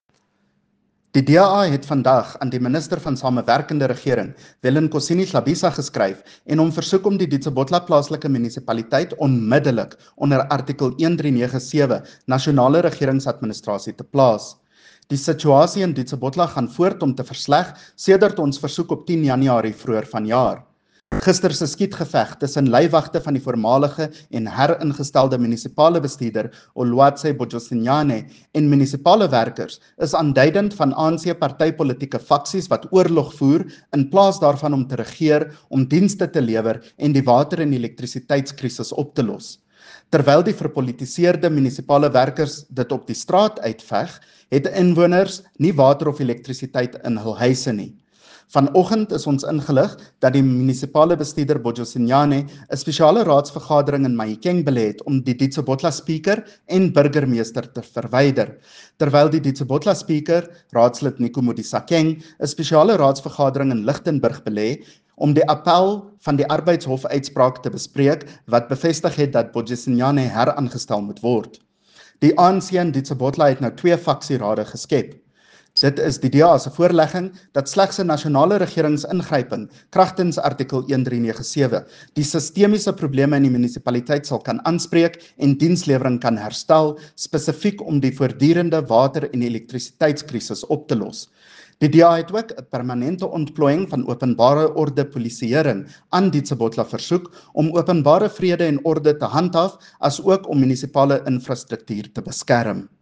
Note to Broadcasters: Please find linked soundbites in English and
Afrikaans by CJ Steyl MPL.